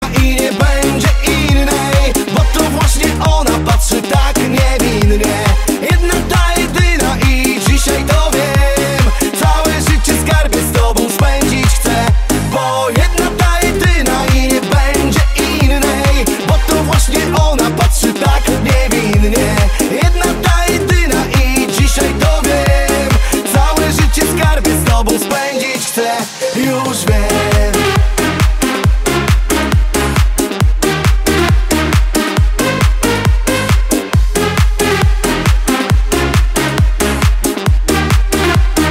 Kategorie Disco Polo